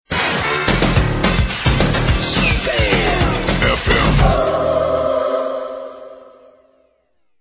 All tracks encoded in mp3 audio lo-fi quality.